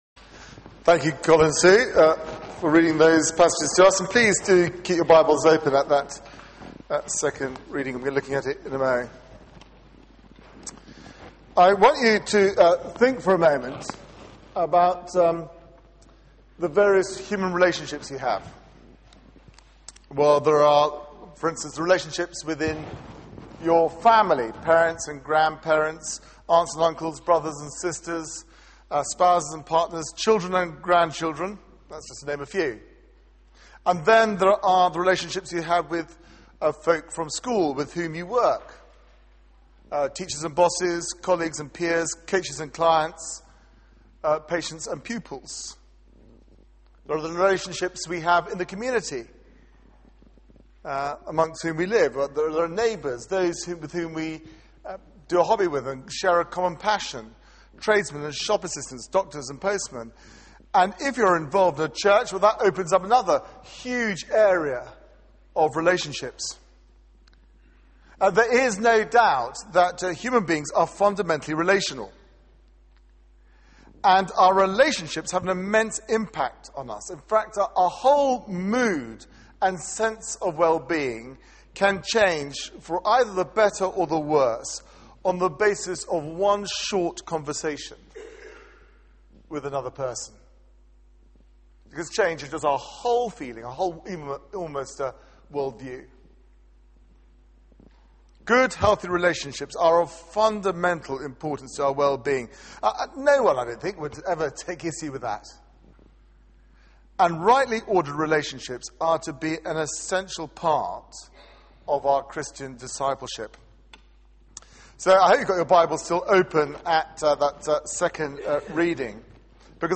Media for 6:30pm Service on Sun 11th Sep 2011
Theme: A distinctive view of relationships Sermon